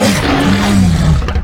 dragonroar.ogg